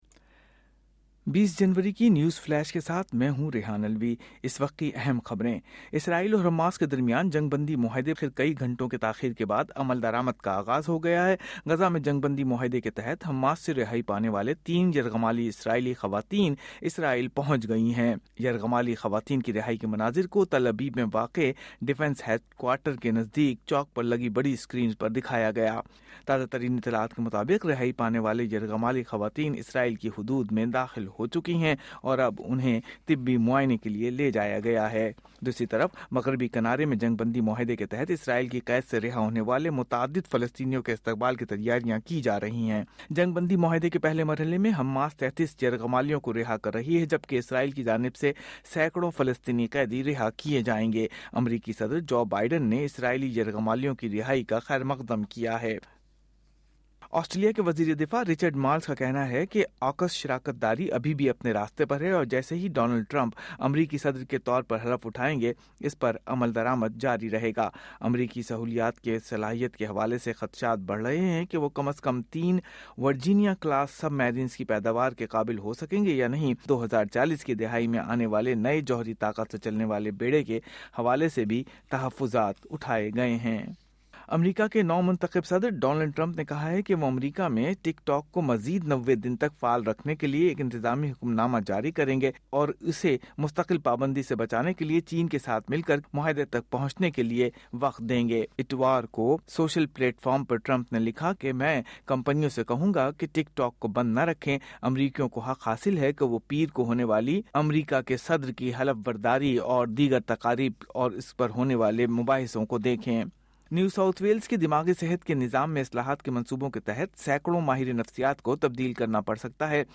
اردو نیوز فلیش: 20 جنوری 2025